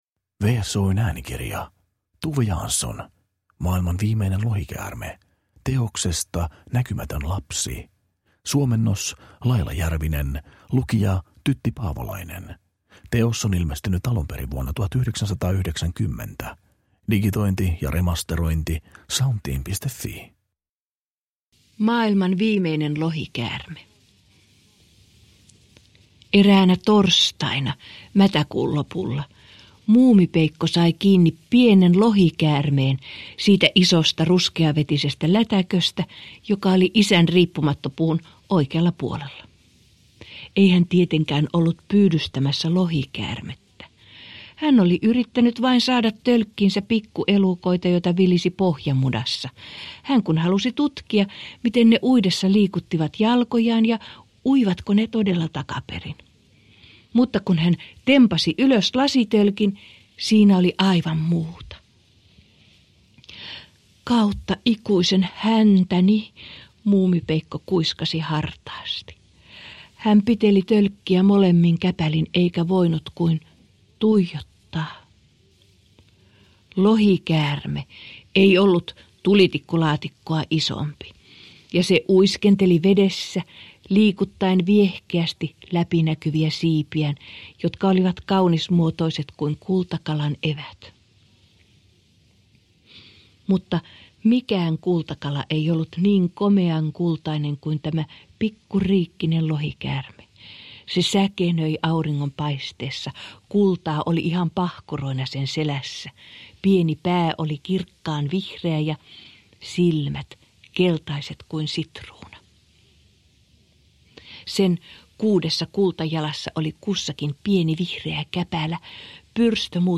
Maailman viimeinen lohikäärme – Ljudbok